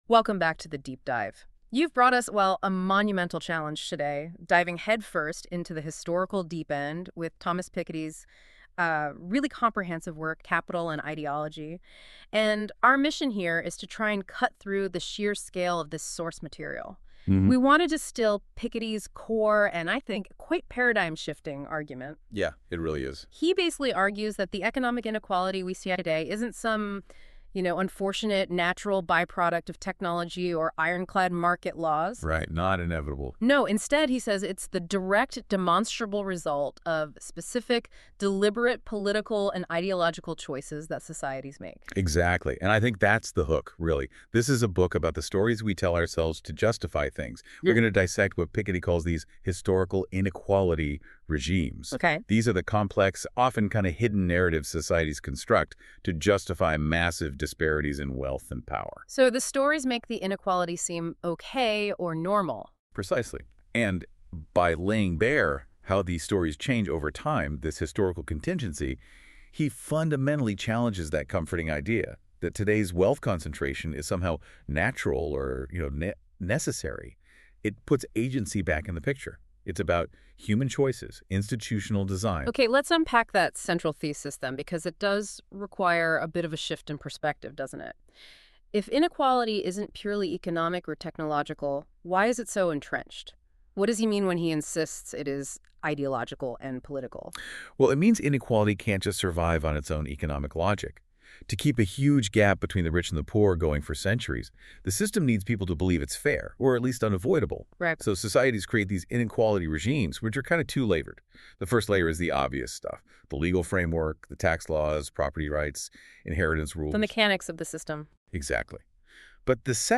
Ekatra audio summary – English